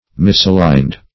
Search Result for " misaligned" : The Collaborative International Dictionary of English v.0.48: misaligned \mis`a*ligned"\ (m[i^]s`[.a]*l[imac]nd"), a. Aligned improperly; not correctly aligned.